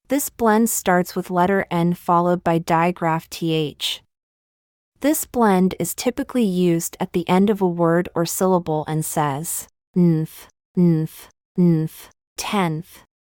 This blend It is typically used at the end of a word or syllable and says: /nth/, /nth/, /nth/, tenth.
NTH-tenth-lesson-AI.mp3